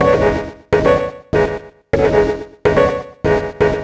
cch_synth_loop_weird_125_D.wav